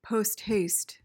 PRONUNCIATION: (post-hayst) MEANING: adverb: With great speed.